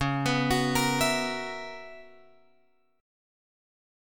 C# Minor 13th